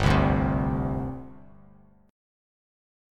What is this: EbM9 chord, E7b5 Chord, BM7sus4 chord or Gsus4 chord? Gsus4 chord